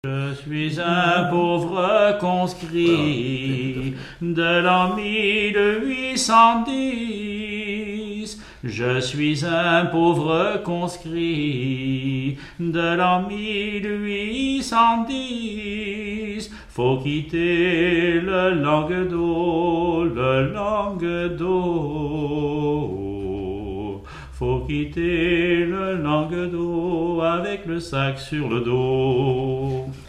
Genre strophique
Catégorie Pièce musicale inédite